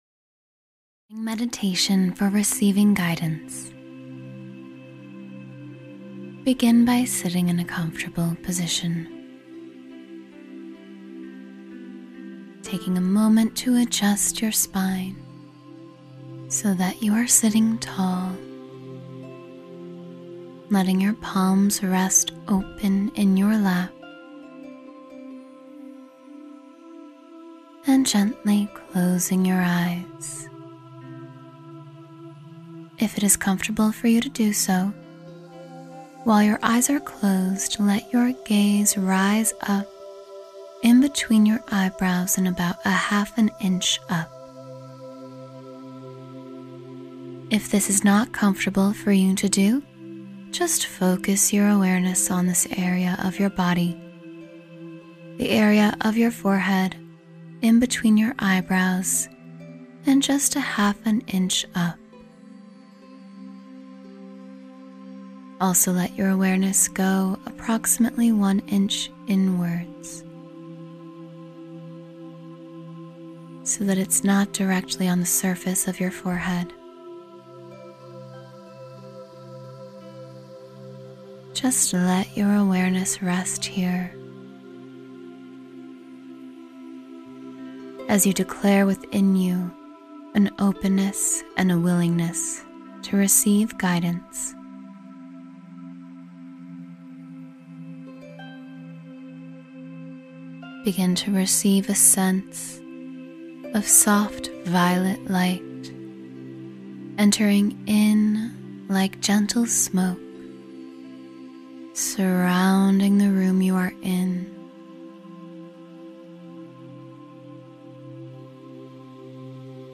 Connect with Your Spirit Guide — Guided Meditation for Divine Guidance